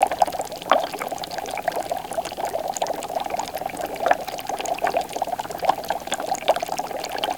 Index of /90_sSampleCDs/E-MU Producer Series Vol. 3 – Hollywood Sound Effects/Water/Bubbling&Streams